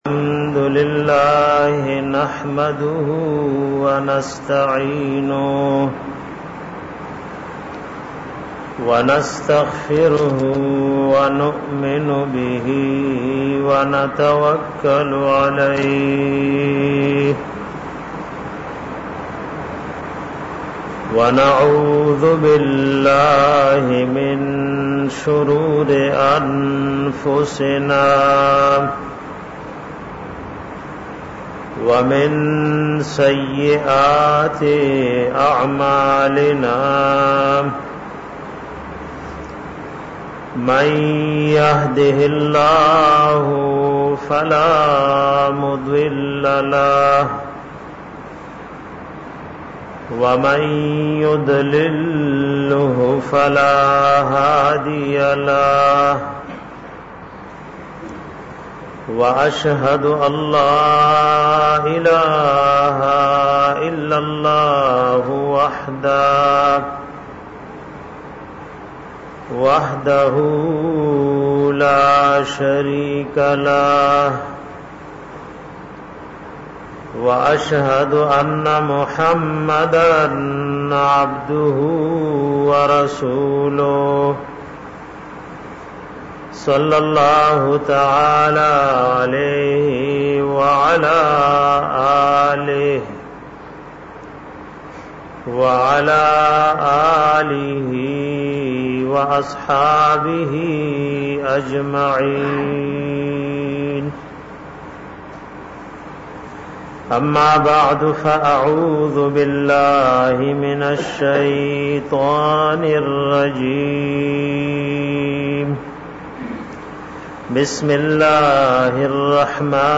bayan pa bara da nabi da mujizato k